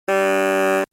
دانلود صدای ماشین 5 از ساعد نیوز با لینک مستقیم و کیفیت بالا
جلوه های صوتی